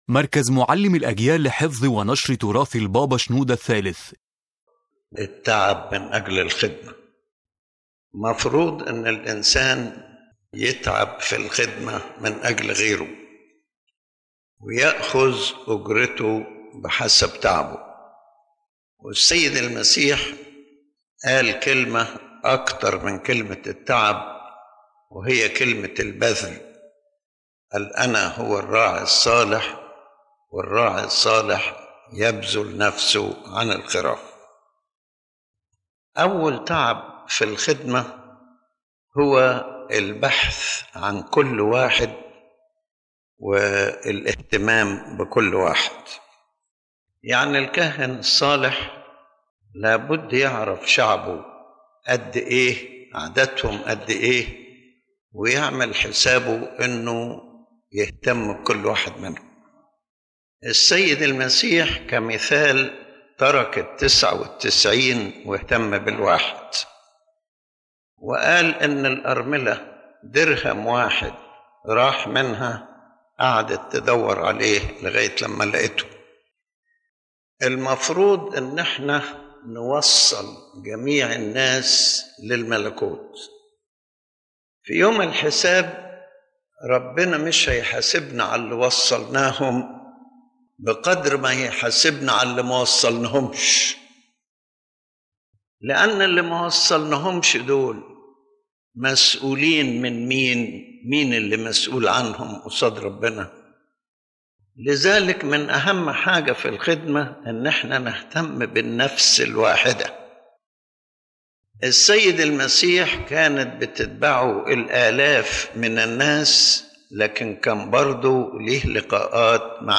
⬇ تحميل المحاضرة الفكرة الأساسية تؤكد المحاضرة أن التعب من أجل الخدمة ليس مجرد جهد خارجي، بل هو بذل محبة ومسؤولية تجاه كل نفس، كما علّمنا السيد المسيح الذي بذل نفسه عن الخراف.